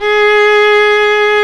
Index of /90_sSampleCDs/Roland - String Master Series/STR_Viola Solo/STR_Vla1 _ marc